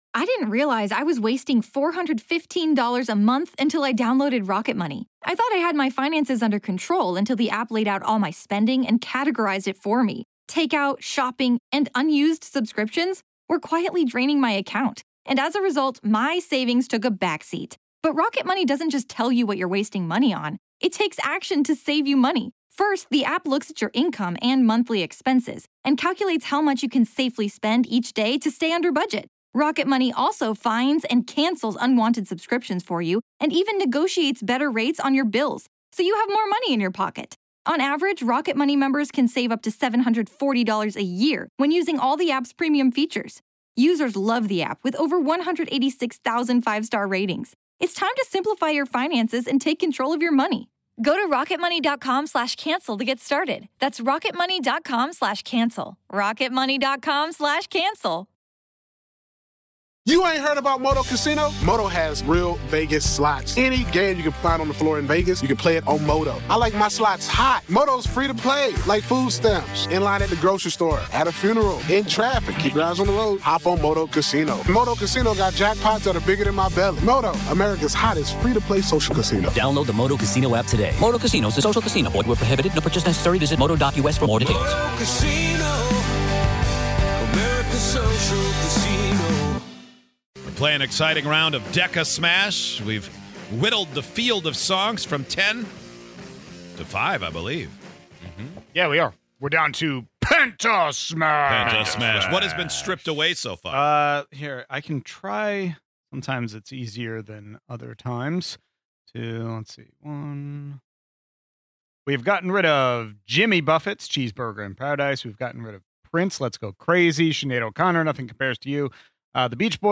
all from musicians who have moved to the afterlife, smashed them all together, and played them at the same time. Can you guess any of the 10 songs from DECASMASH?